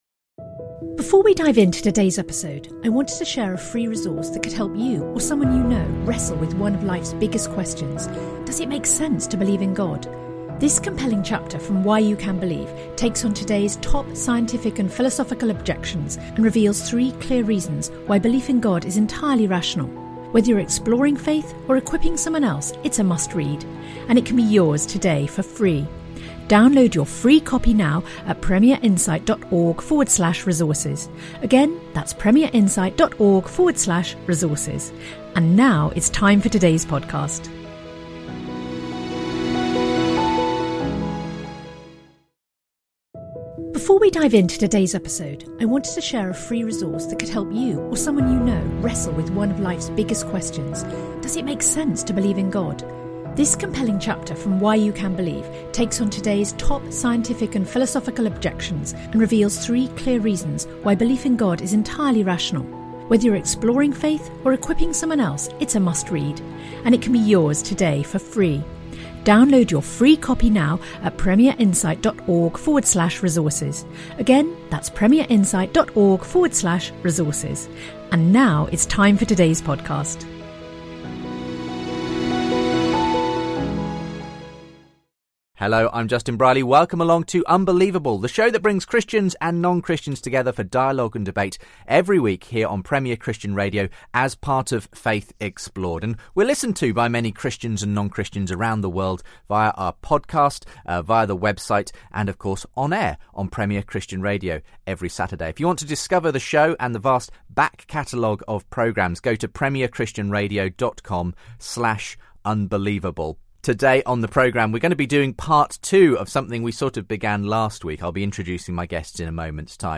Christianity, Religion & Spirituality